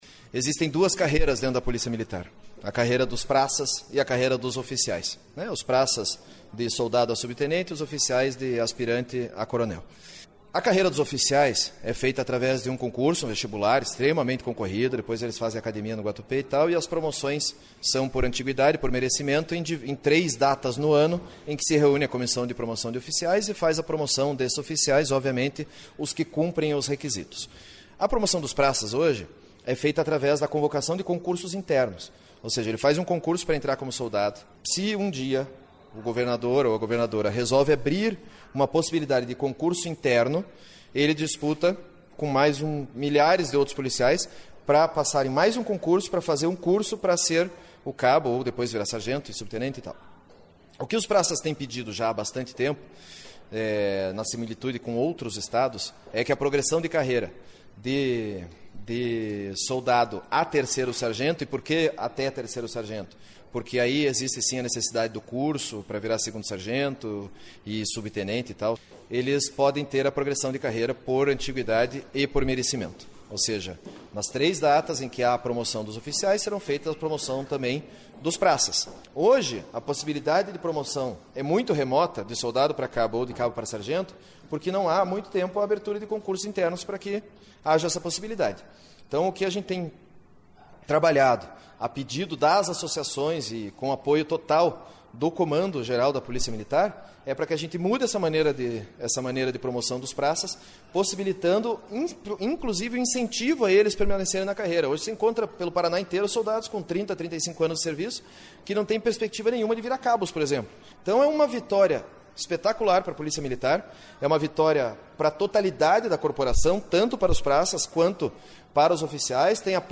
O deputado Pedro Lupion (DEM), que foi o relator na proposta da CCJ, eplica a importância do Plano de Carreira para os praças da Polícia Militar do Paraná.